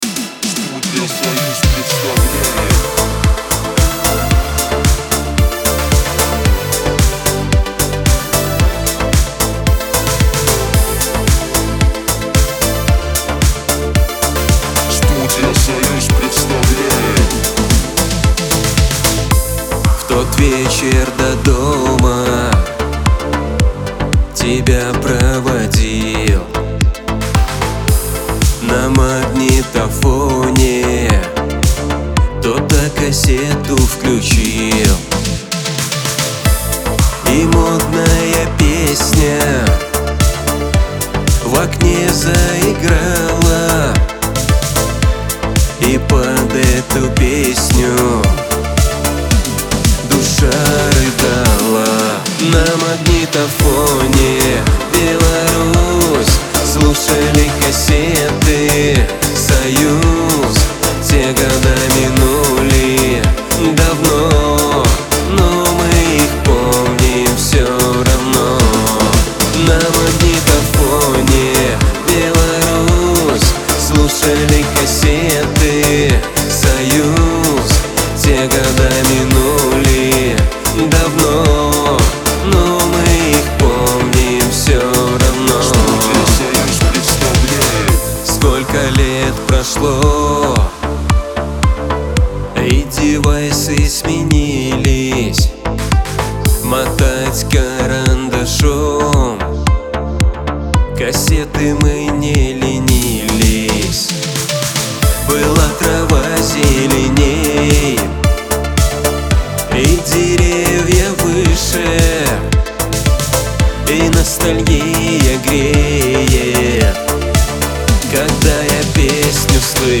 диско , весёлая музыка
pop